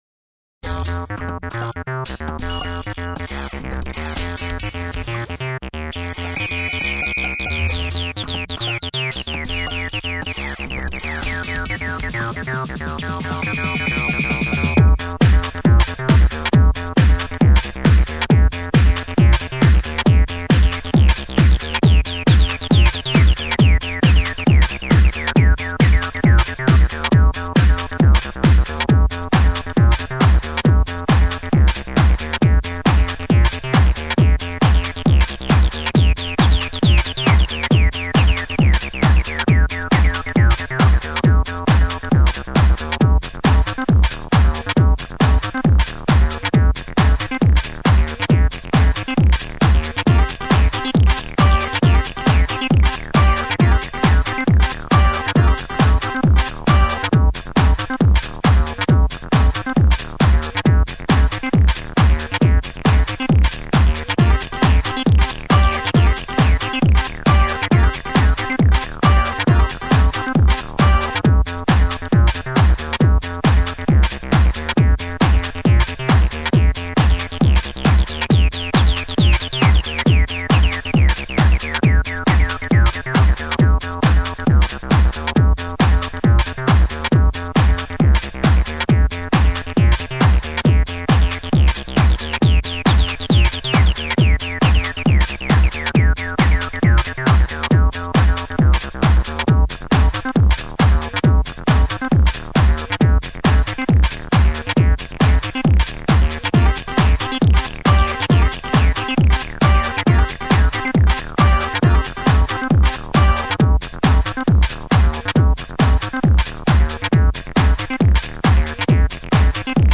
(Dance-Techno)